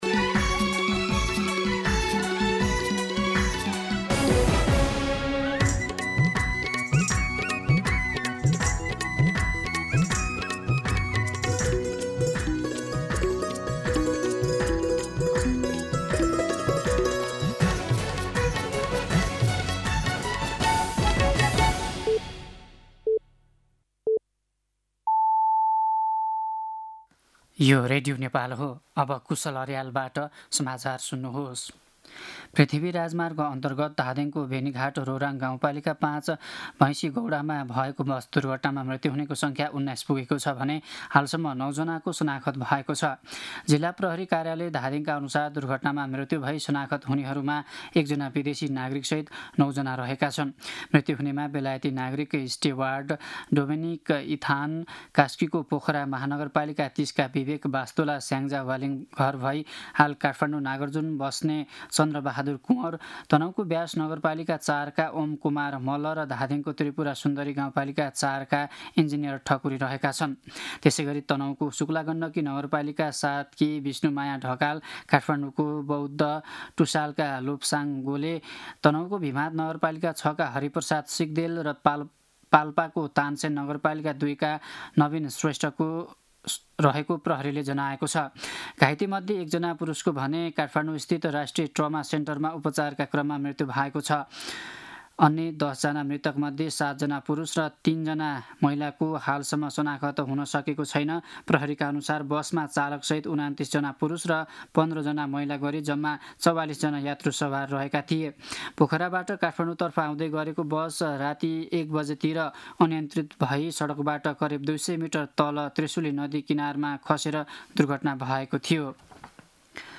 दिउँसो ४ बजेको नेपाली समाचार : ११ फागुन , २०८२